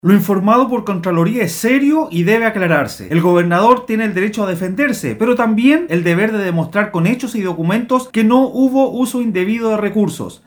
En medio de este contexto, desde la oposición calificaron esta situación como “grave”, sin embargo, el diputado Eduardo Durán (RN) planteó que Orrego tiene todo el derecho a defenderse de las acusaciones, pero debe demostrar que es inocente de lo que se le acusa.